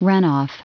Prononciation du mot runoff en anglais (fichier audio)
Prononciation du mot : runoff